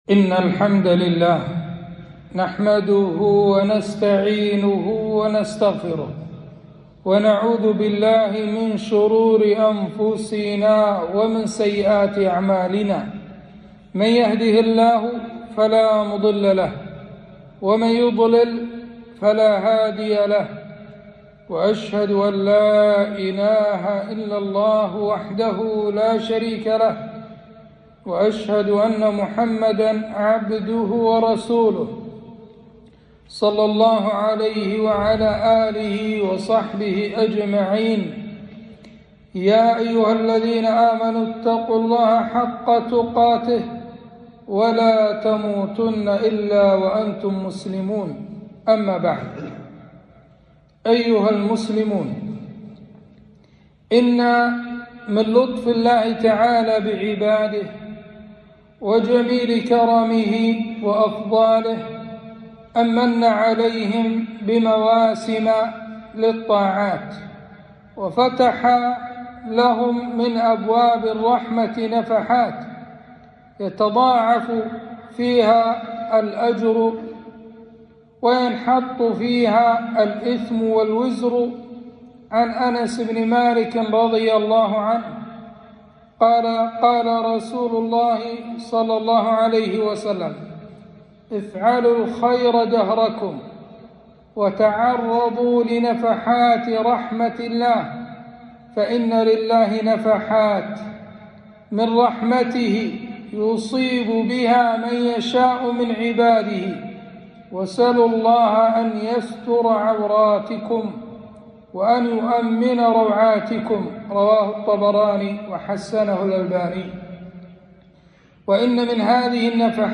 خطبة - استقبال شهر رمضان